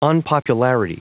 Prononciation du mot : unpopularity